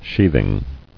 [sheath·ing]